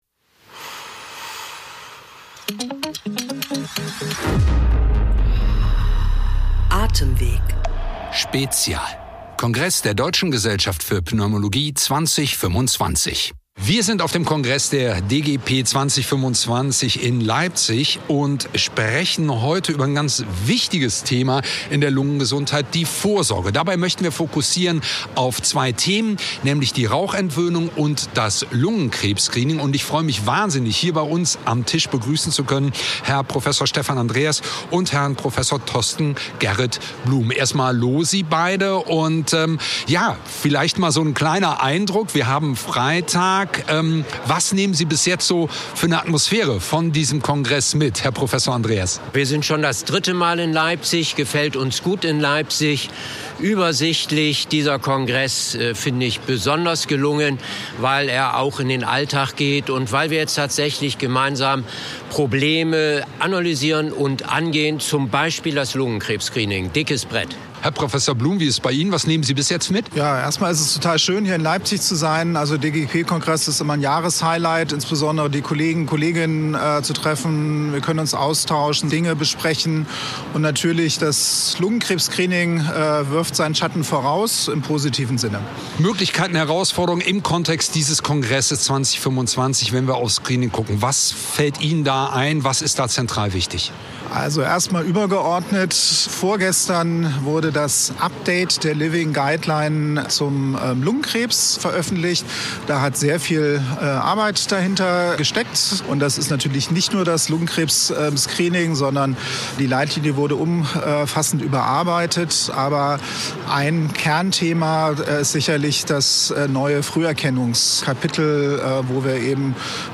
Direkt vom 65. DGP-Kongress in Leipzig: In unserer dreiteiligen